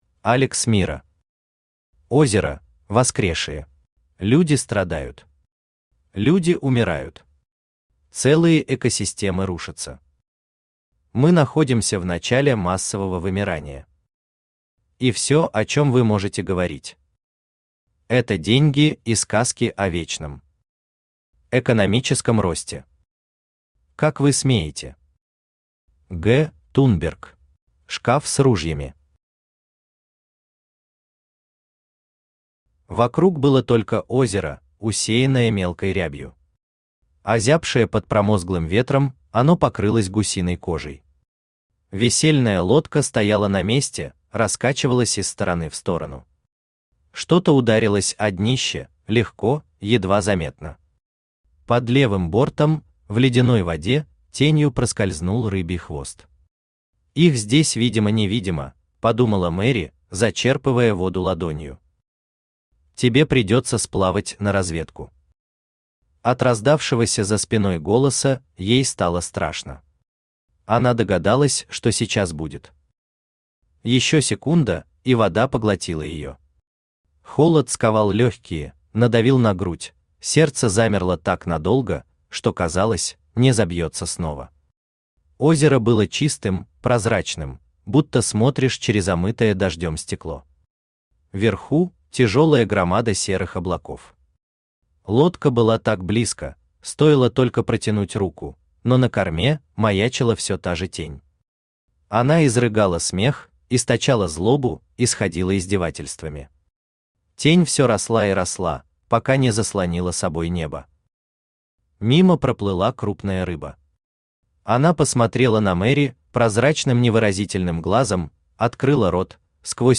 Аудиокнига Озеро: воскресшие | Библиотека аудиокниг
Aудиокнига Озеро: воскресшие Автор Алекс Миро Читает аудиокнигу Авточтец ЛитРес.